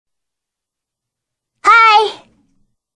女孩打招呼音效_人物音效音效配乐_免费素材下载_提案神器